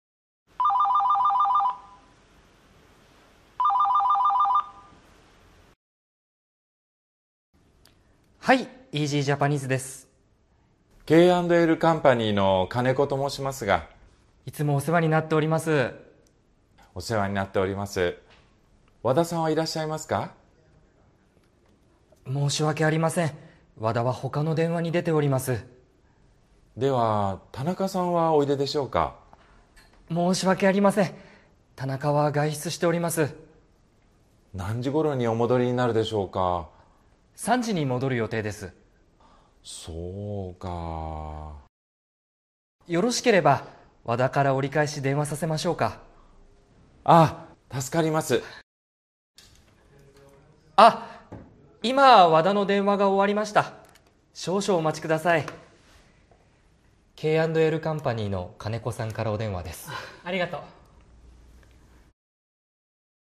English Title: Answering phone calls for others